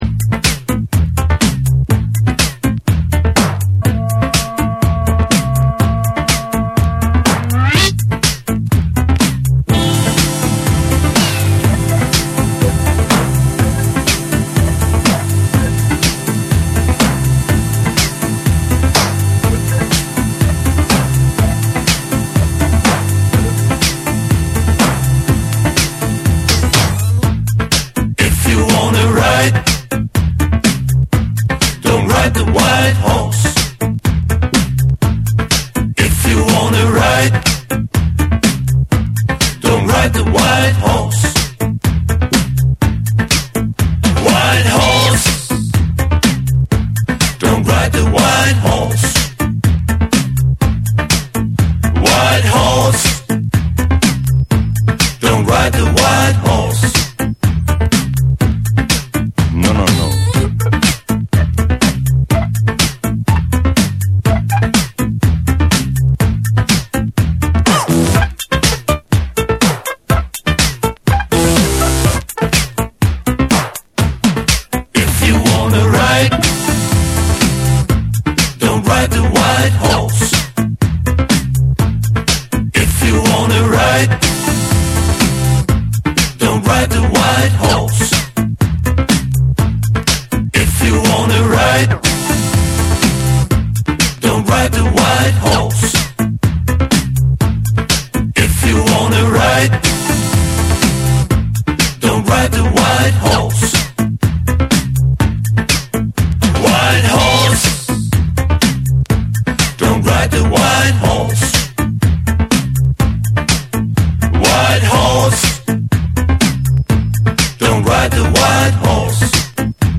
DANCE CLASSICS / DISCO / RE-EDIT / MASH UP